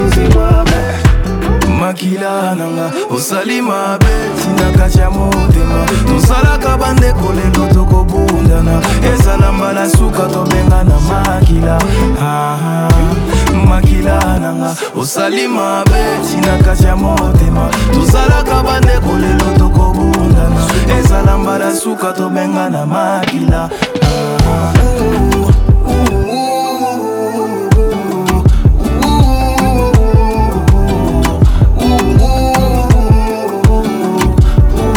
Жанр: Африканская музыка / Поп